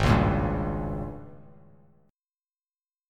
G#M7sus2sus4 chord